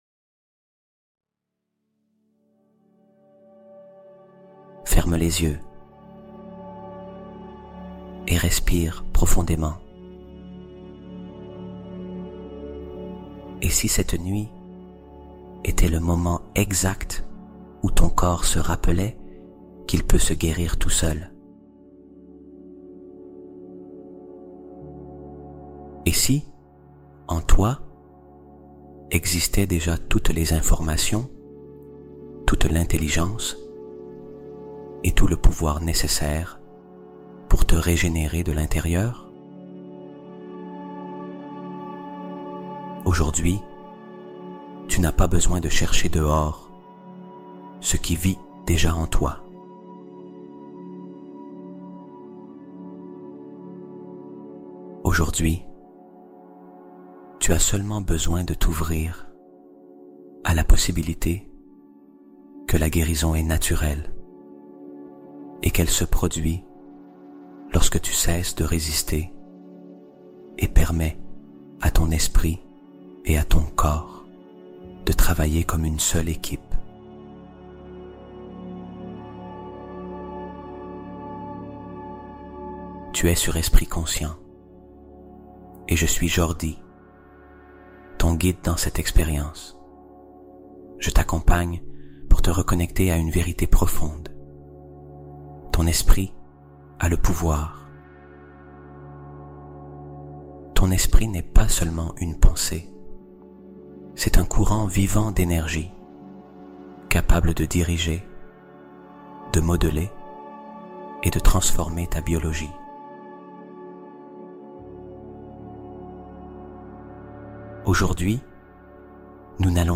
Ton Corps Peut Se Guérir Lui-Même : Découvre Le Pouvoir Caché de Ton Esprit (Méditation Guidée)